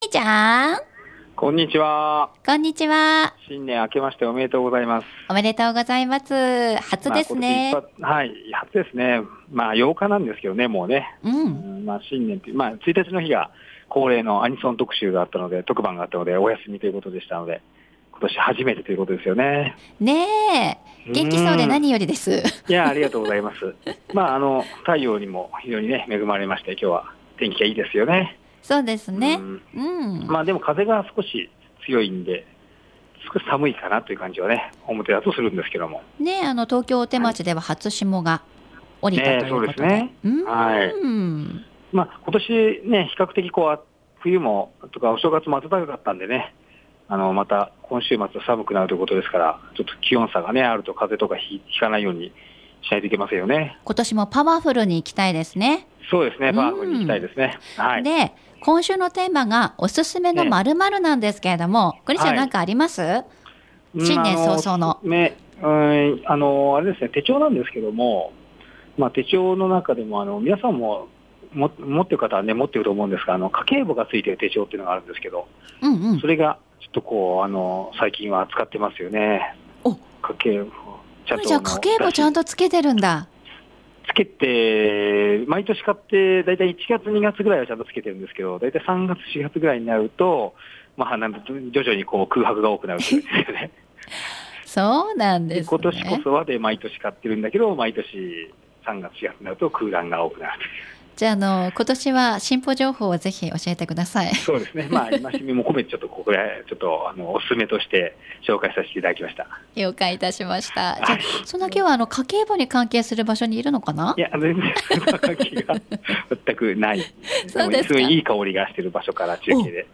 街角レポート